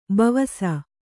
♪ bavasa